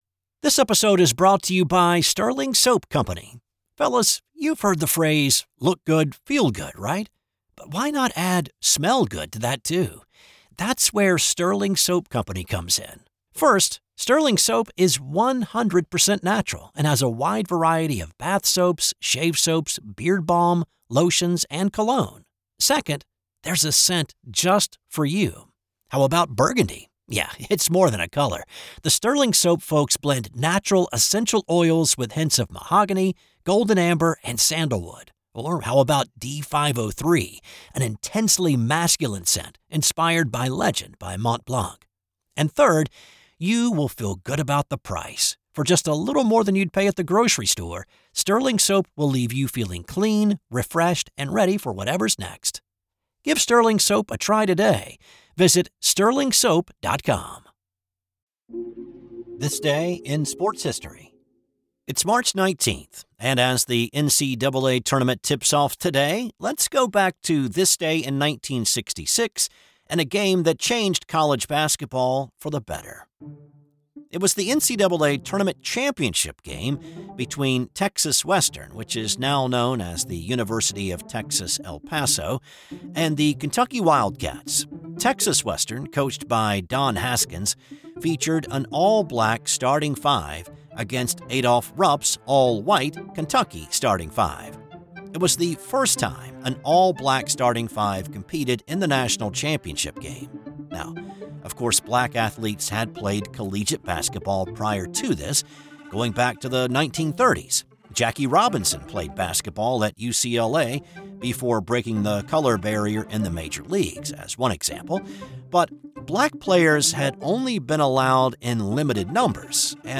'This Day in Sports History' is a one person operation.